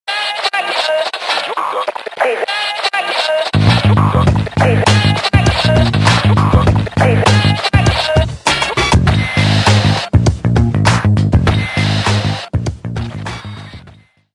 nokia-retrace_24892.mp3